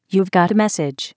notif.wav